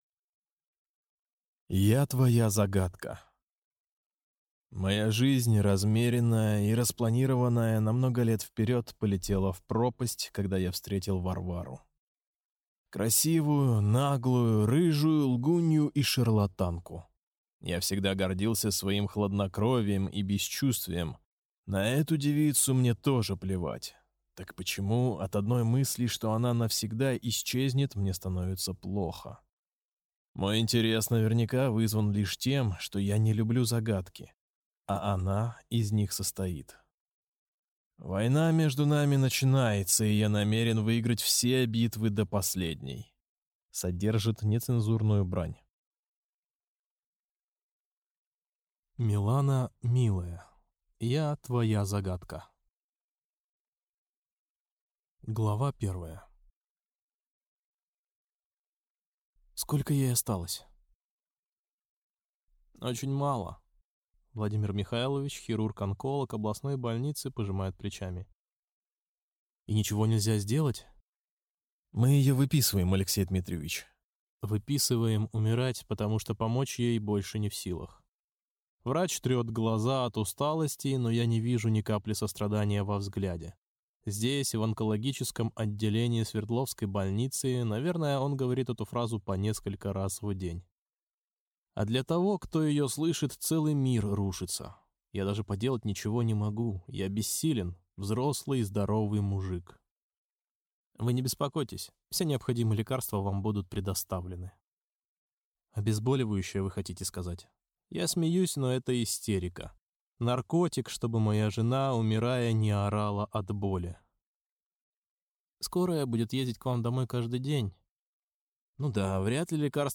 Аудиокнига Я твоя Загадка | Библиотека аудиокниг